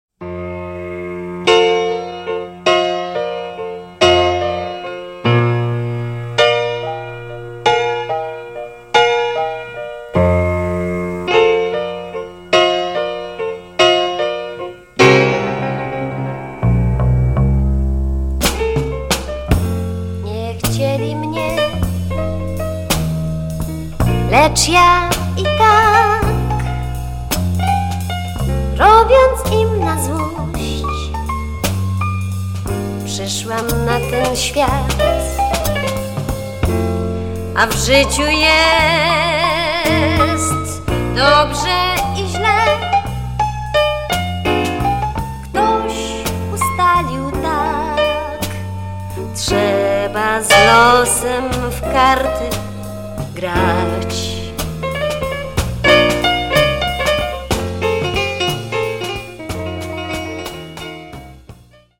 Nagrania radiowe (1970):